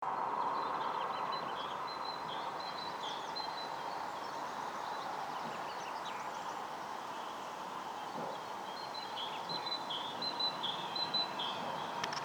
большая синица, Parus major
СтатусПоёт